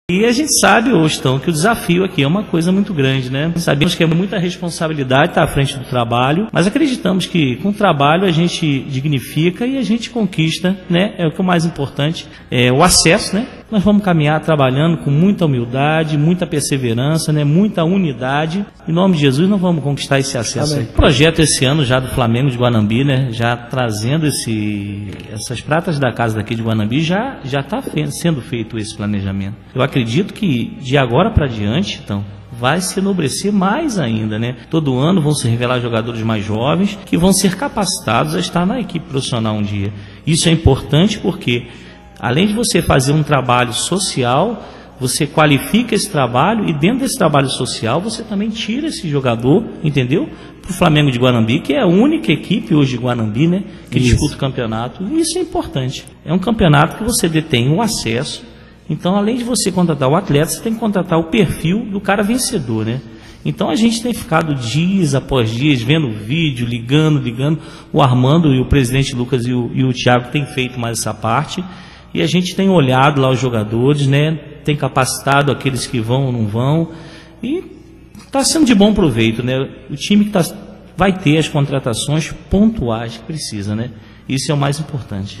Na noite da quarta feira 18, estiveram presentes no Programa Balanço Esportivo da Rádio Cultura de Guanambi, Diretoria e comissão técnica do Flamengo de Guanambi falando sobre a expectativa de estreia do Campeonato Baiano 2ª Divisão 2015.